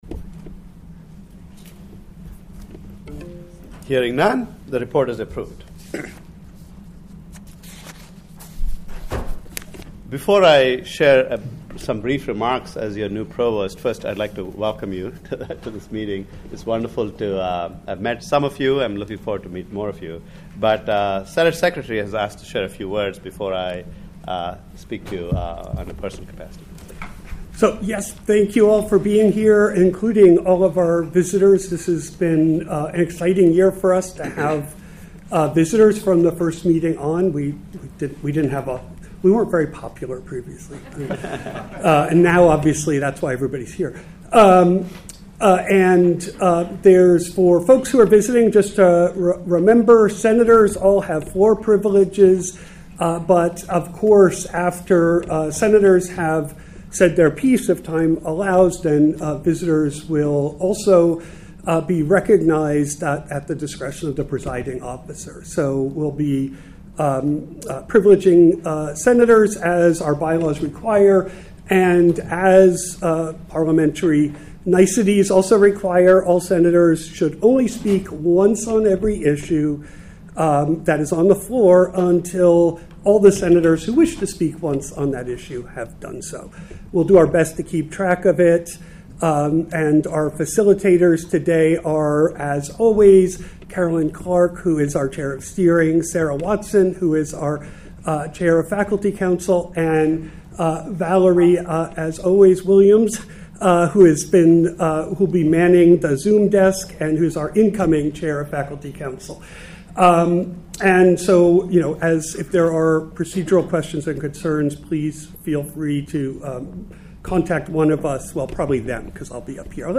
Meeting Recording
This meeting will be a hybrid meeting.